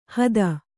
♪ hada